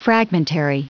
Prononciation du mot fragmentary en anglais (fichier audio)
Prononciation du mot : fragmentary